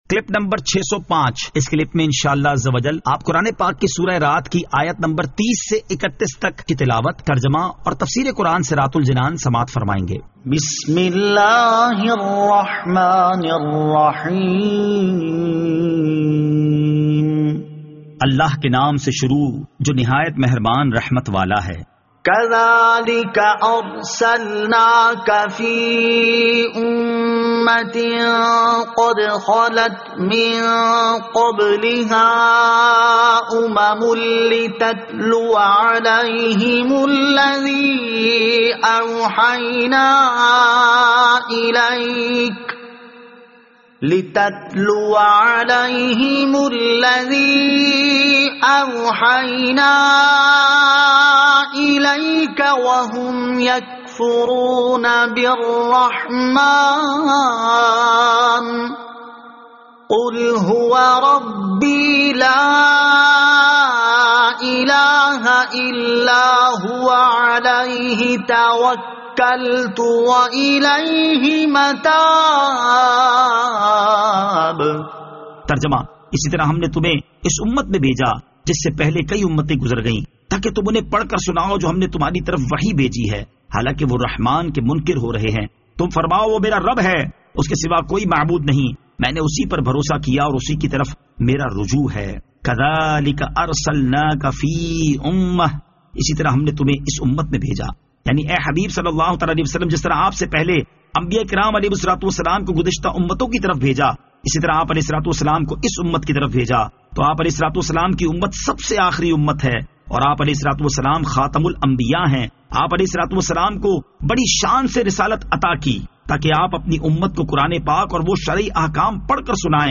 Surah Ar-Rad Ayat 30 To 31 Tilawat , Tarjama , Tafseer